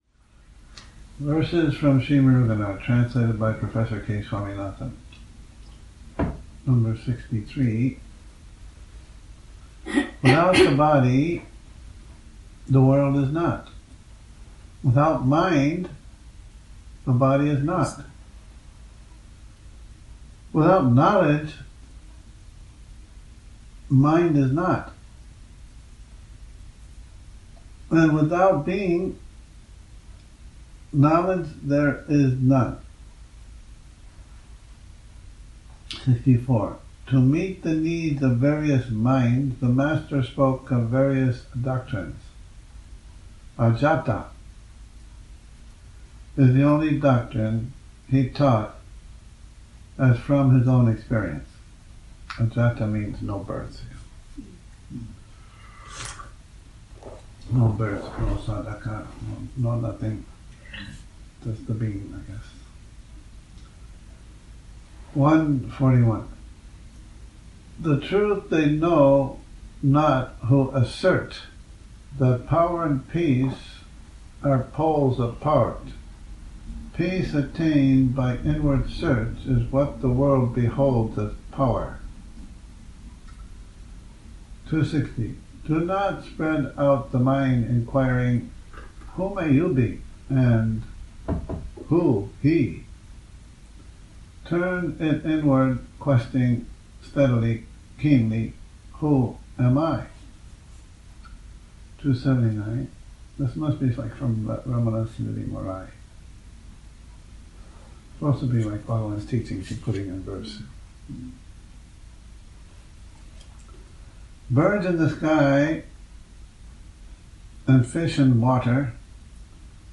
Morning Reading, 16 Nov 2019